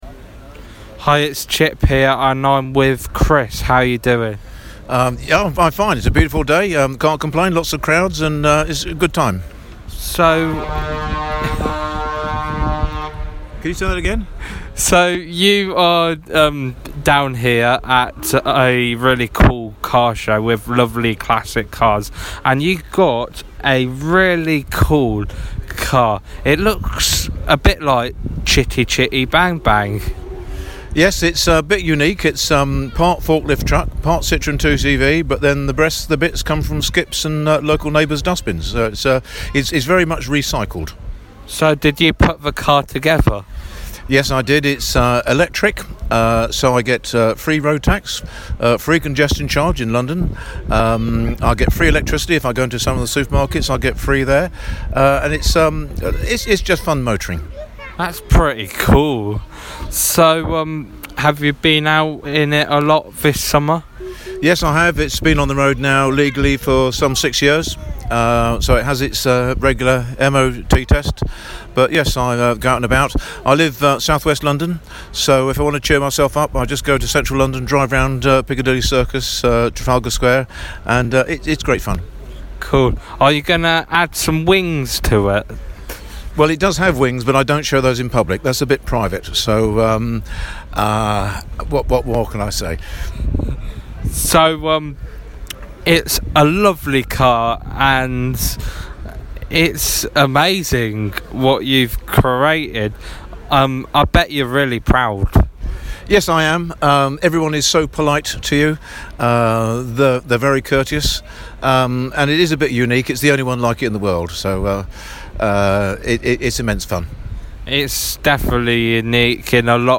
Interview Classic Car Show